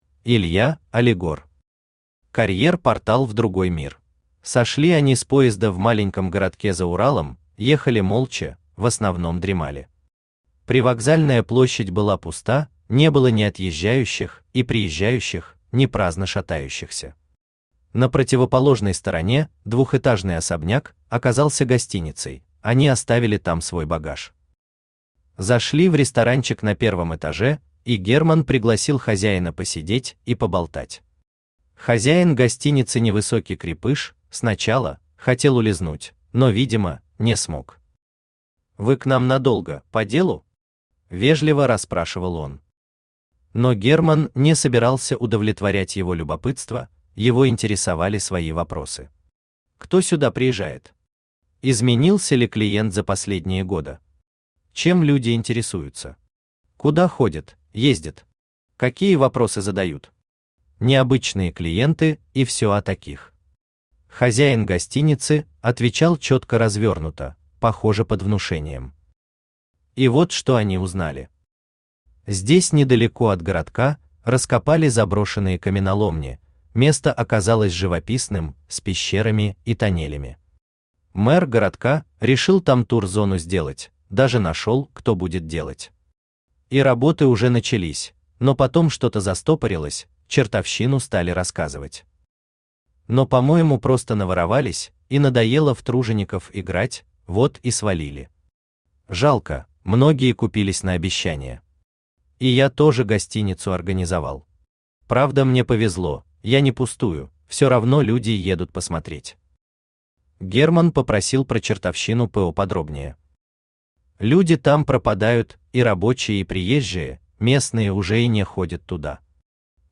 Аудиокнига Карьер Портал в другой мир | Библиотека аудиокниг
Aудиокнига Карьер Портал в другой мир Автор Илья Алигор Читает аудиокнигу Авточтец ЛитРес.